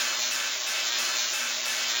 SFX / Room / Tesla / Idle.ogg